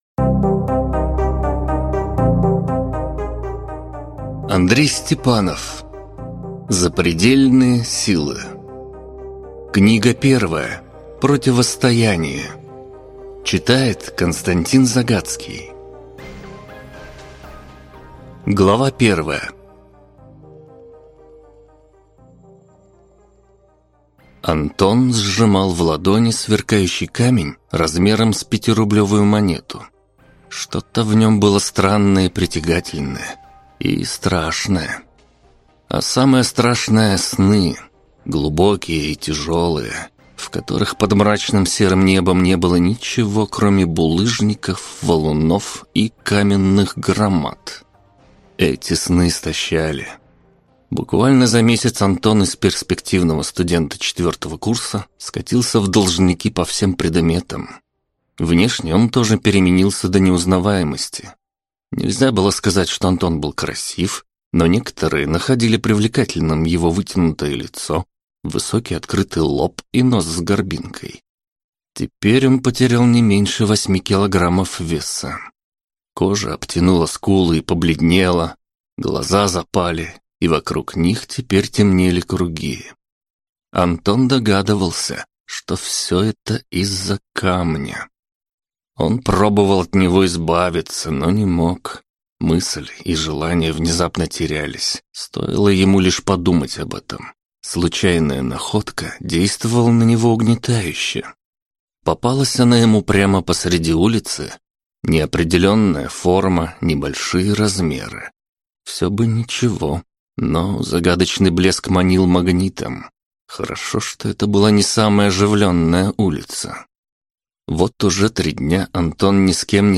Аудиокнига Запредельные силы: Противостояние | Библиотека аудиокниг